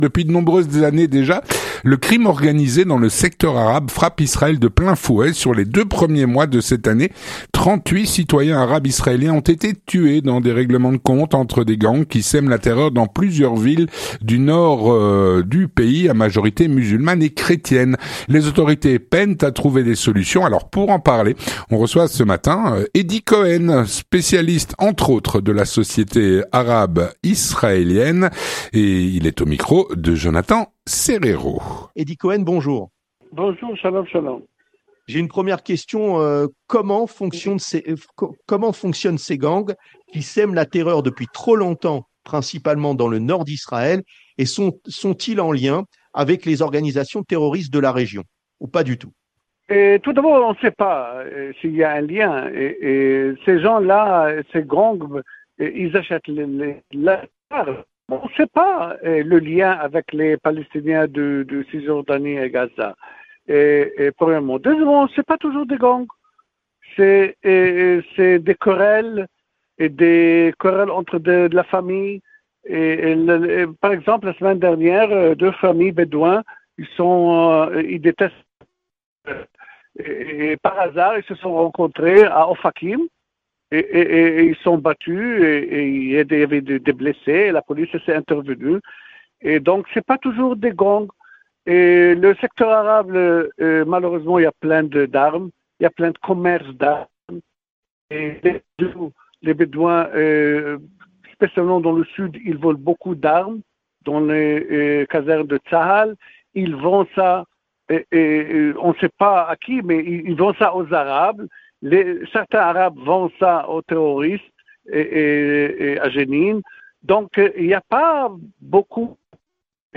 L'entretien du 18H - Le crime organisé dans le secteur arabe frappe Israël de plein fouet.